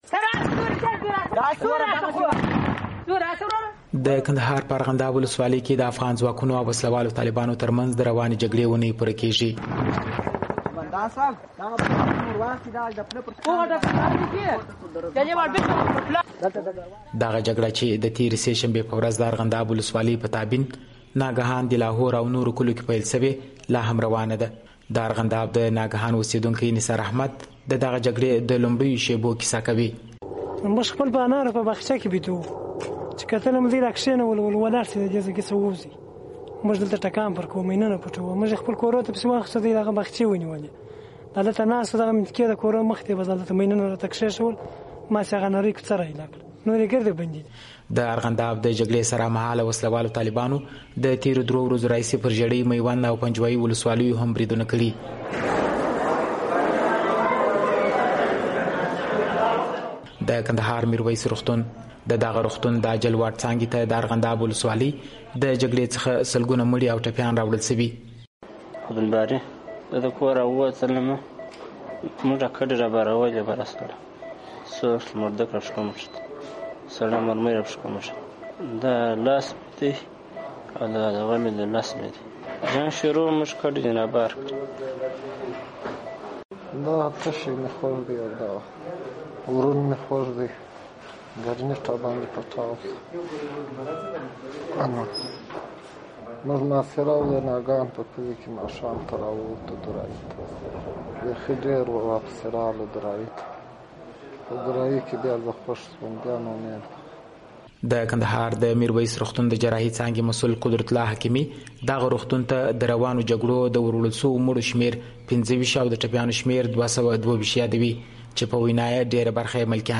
د کندهار په اړه راپور